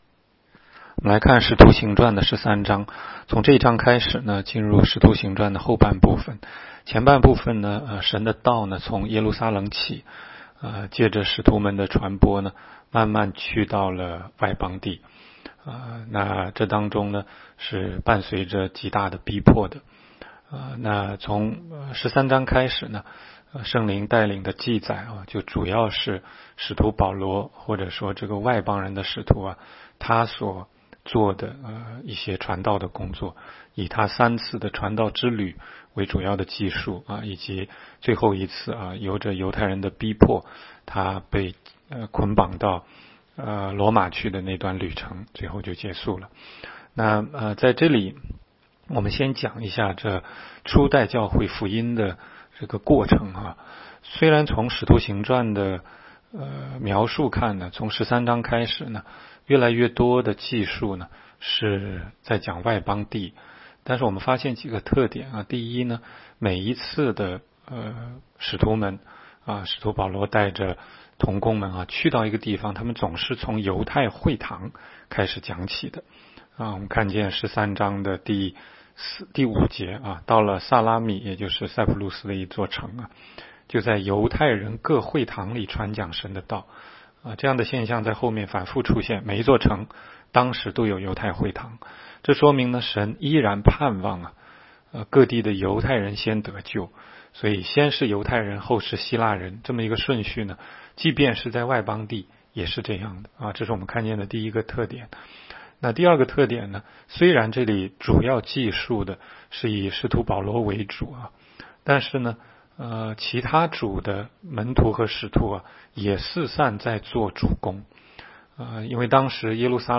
16街讲道录音 - 每日读经-《使徒行传》13章